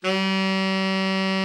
TENOR 12.wav